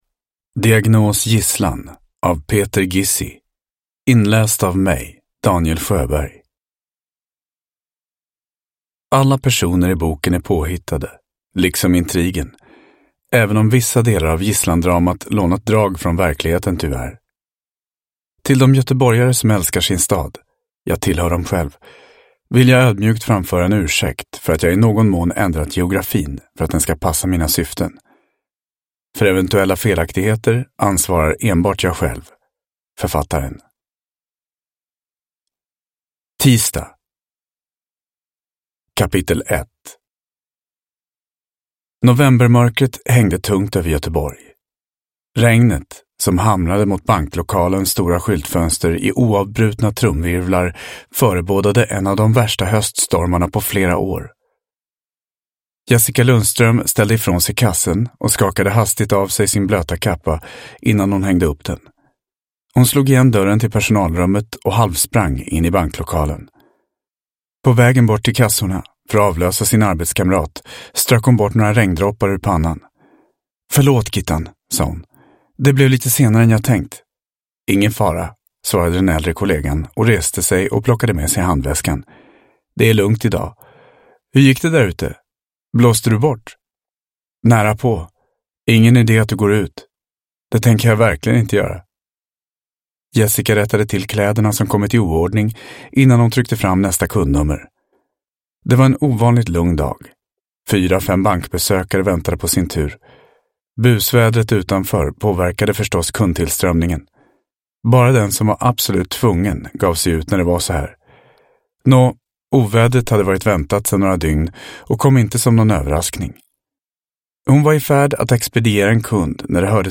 Diagnos: gisslan – Ljudbok – Laddas ner